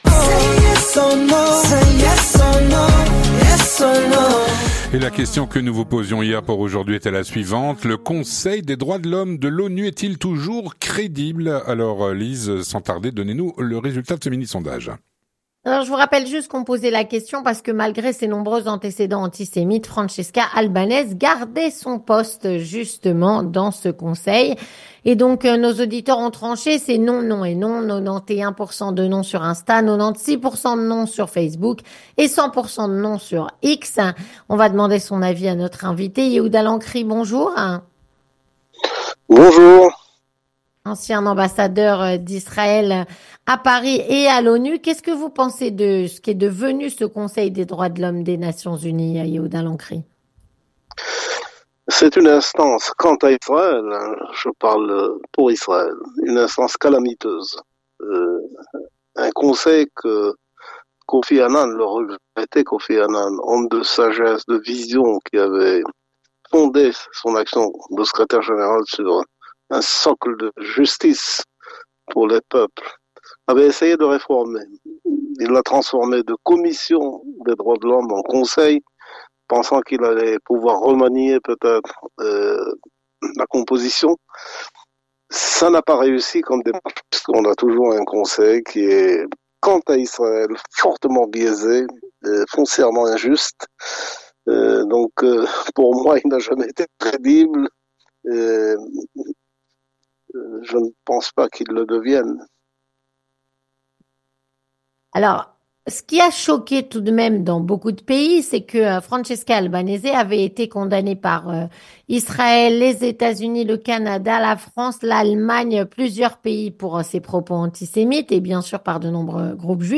Yehuda Lancry, ancien ambassadeur d'Israël en France et à l’ONU, répond à "La Question Du Jour".